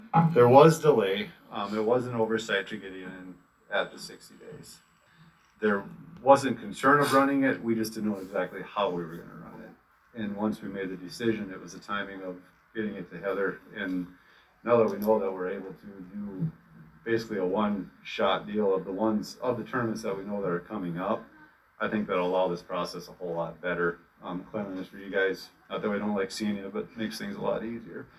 Mobridge City Council discusses mask policies